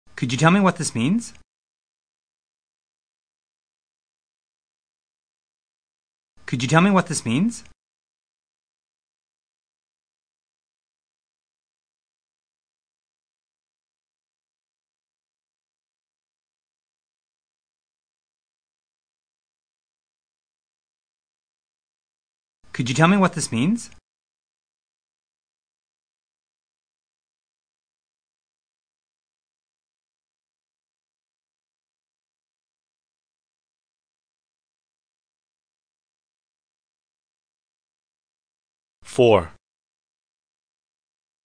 Speech Communication Dictation
Form-Focused Dictation 1: Wh. vs. Yes/No Questions (intonation patterns)